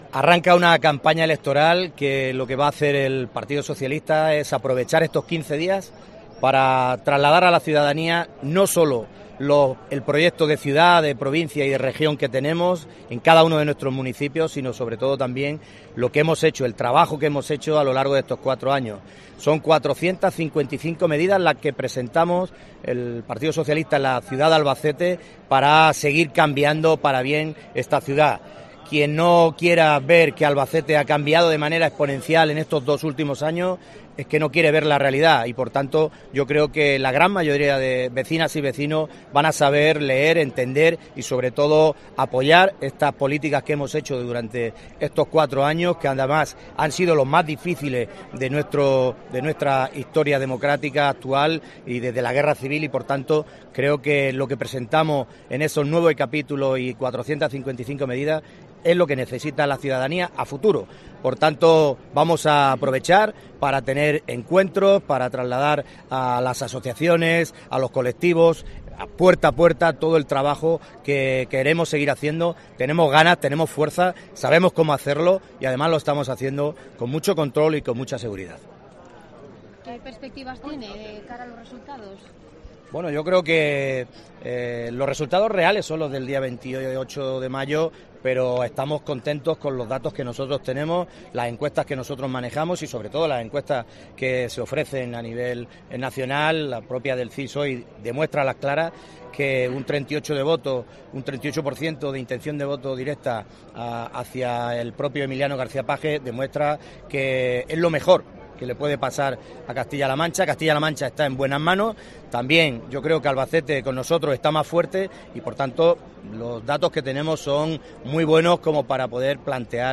El Partido Socialista, en la Plaza del Altozano
Declaraciones Emilio Sáez- Altozano